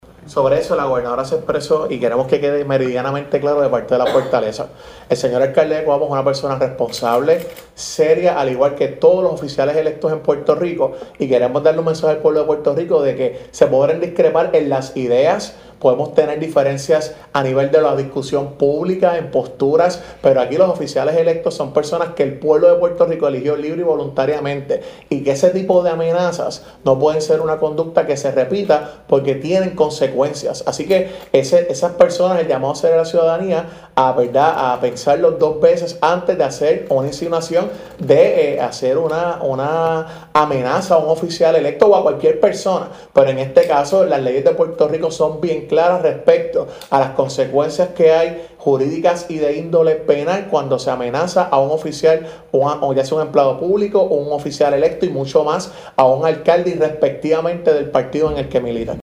Tras darse a conocer que el alcalde del municipio de Coamo Juan Carlos García Padilla, ha estado bajo amenaza de muerte luego que se revelara  el plan a través de la Policía de Puerto Rico,  el secretario de Asuntos Públicos de la Fortaleza, Jean Peña Payano, dijo: